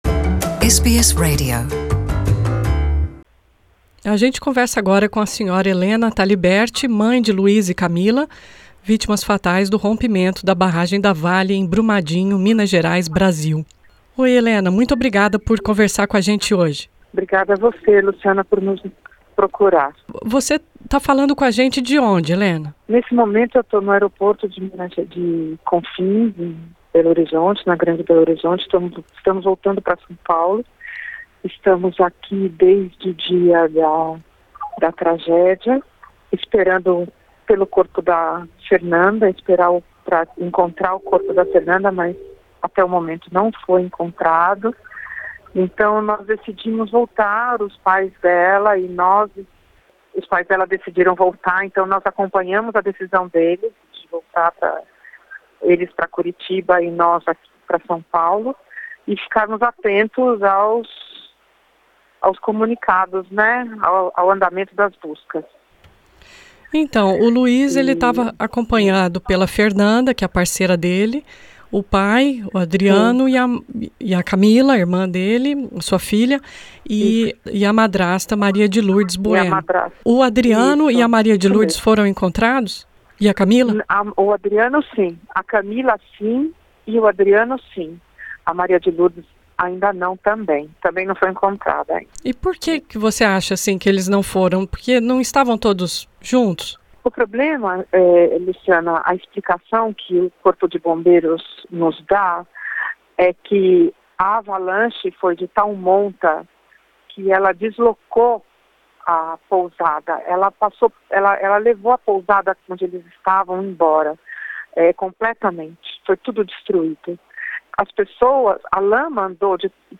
do aeroporto de Belo Horizonte, Minas Gerais